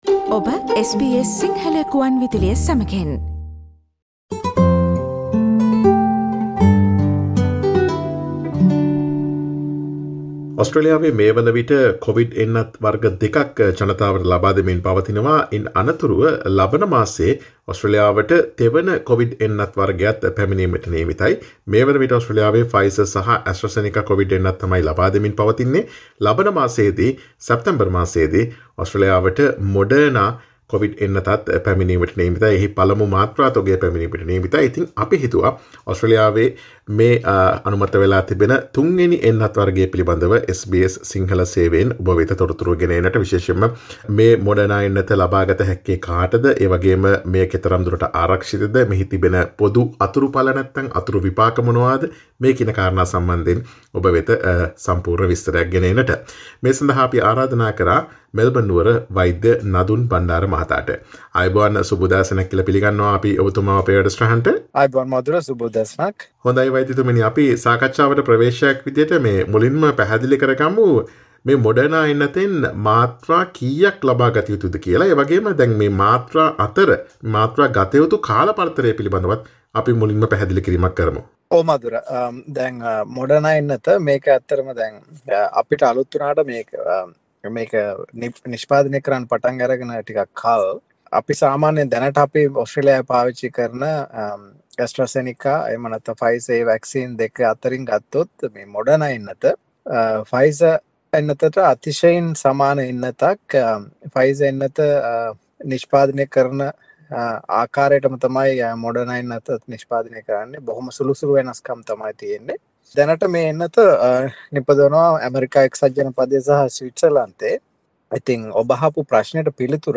ඔස්ට්‍රේලියාවේ ලබාදීමට නියමිත 3වන කොවිඩ් එන්නත ලෙස Moderna එන්නතට පසුගියදා අනුමැතිය හිමිවිය. Moderna එන්නත ලබාගත හැකි පිරිස්, එහි ඇති කාර්යක්ෂමතාව මෙන්ම අතුරු ආබාධ පිළිබඳව SBS සිංහල ගුවන් විදුලිය සිදුකළ සාකච්ඡාවට සවන් දෙන්න.